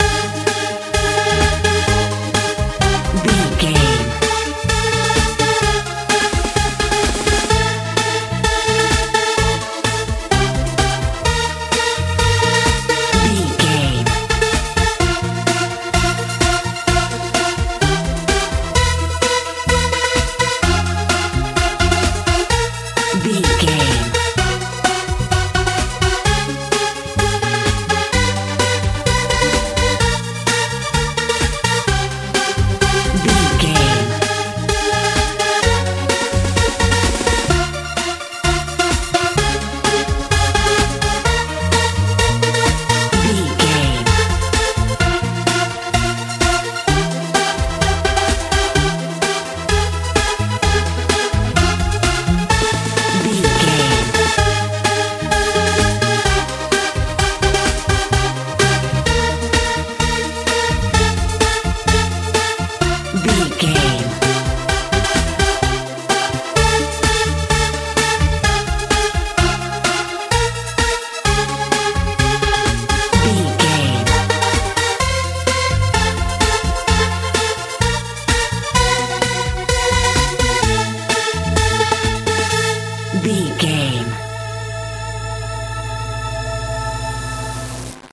techno feel
Ionian/Major
A♭
bass guitar
synthesiser
drums
tension
suspense
strange